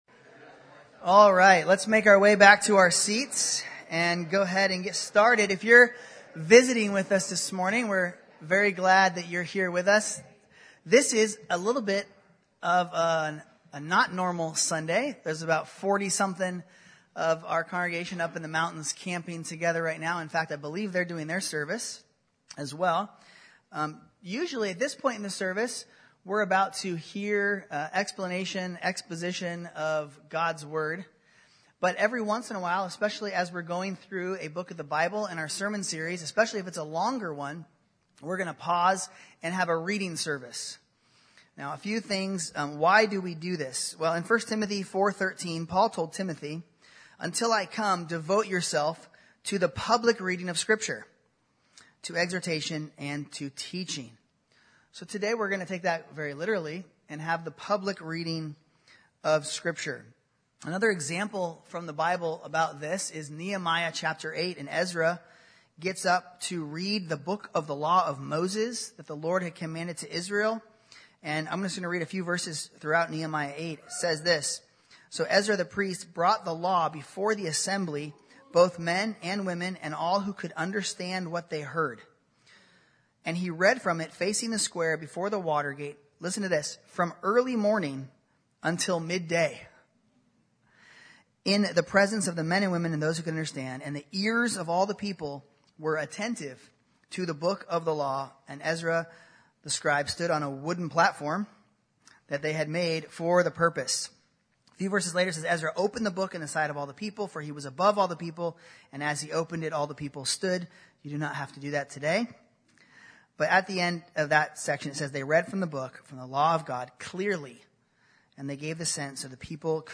Reading Service (John 1-8)